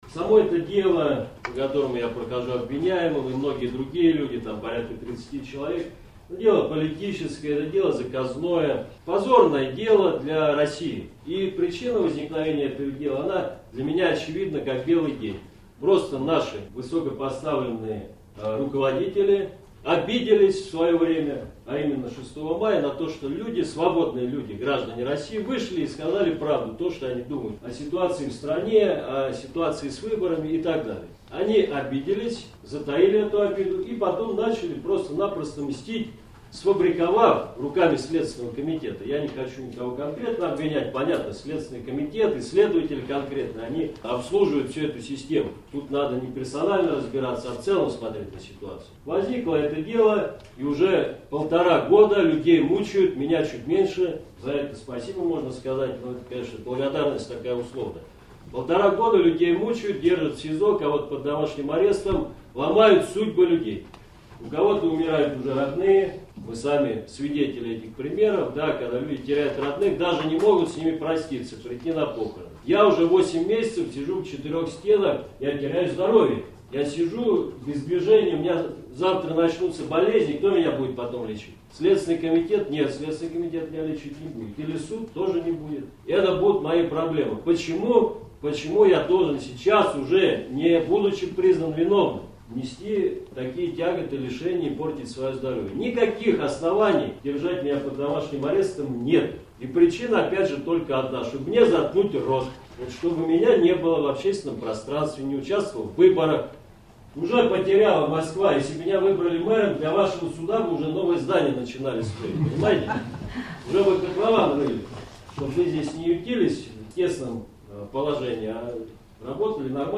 Речь Сергея Удальцова в суде